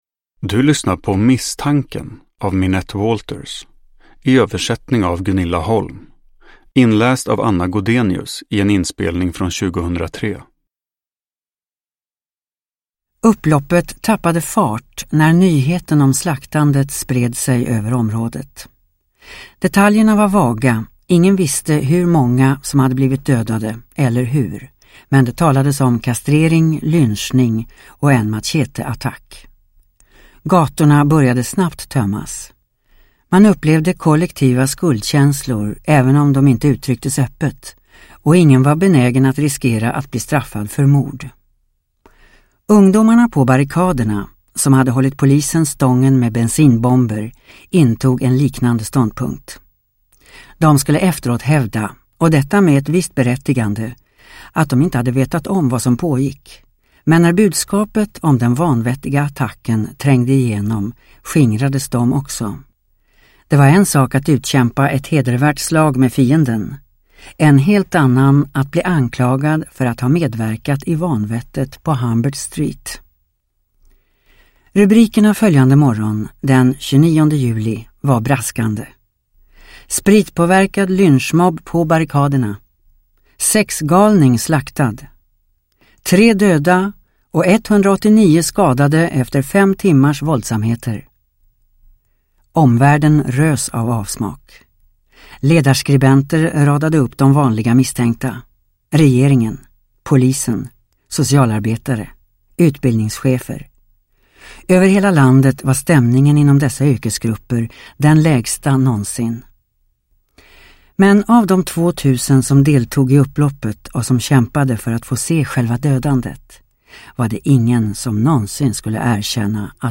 Misstanken – Ljudbok – Laddas ner